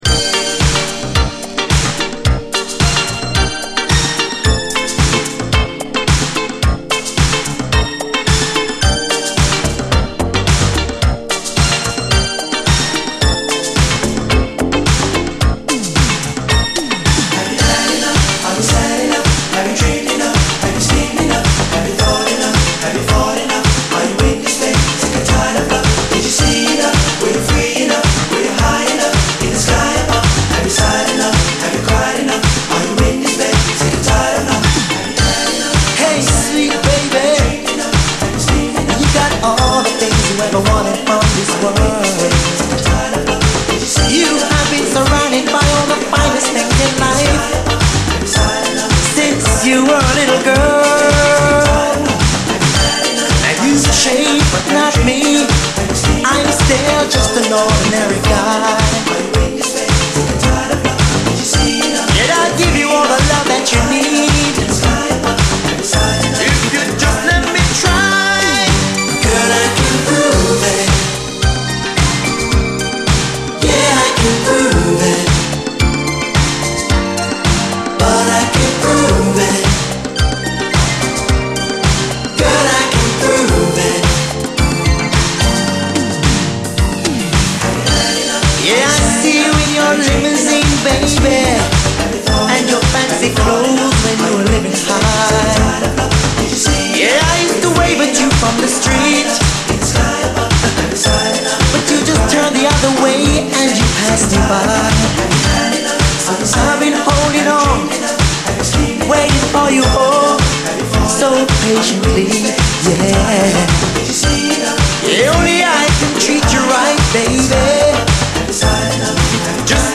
流麗ズンドコ・ディスコ